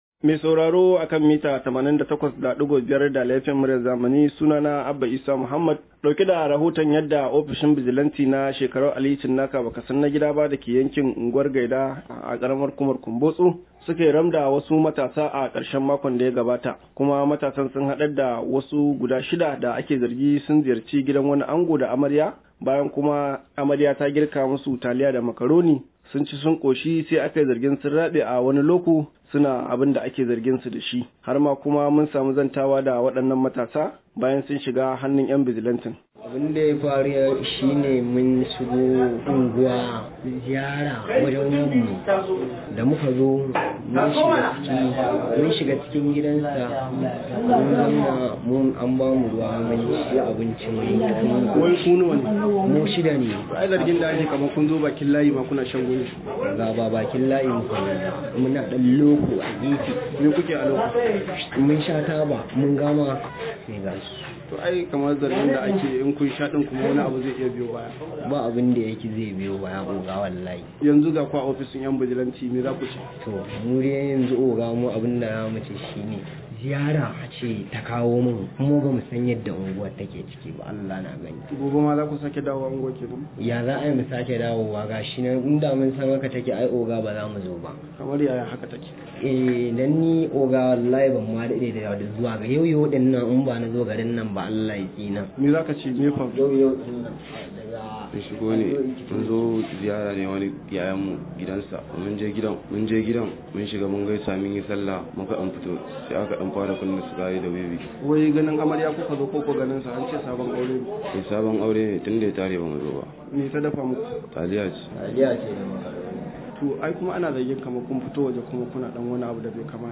Rahoto:  Matasan da suka ɓuge shaye-shaye daga zuwa cin abincin amarya sun shiga hannu